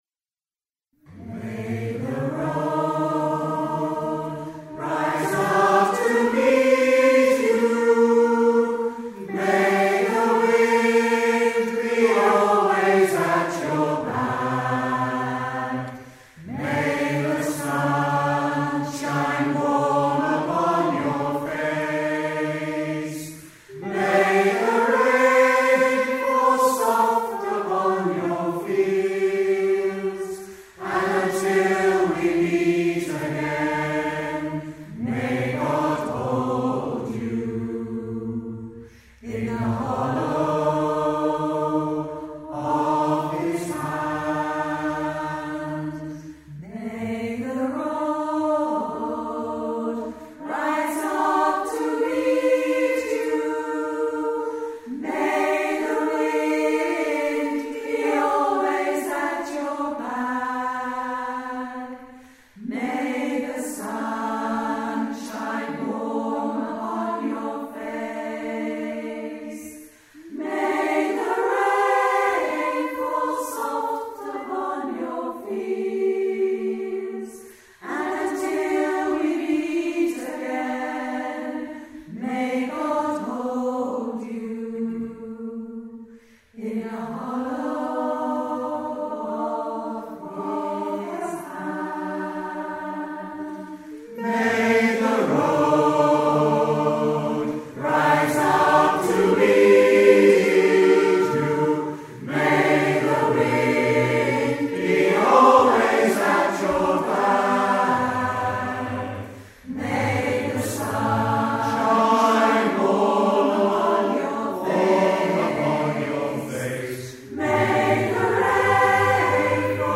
Zvuková ukázka z vystoupení v kostele sv. Jan Evangelisty v Towbridge